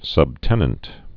(sŭb-tĕnənt)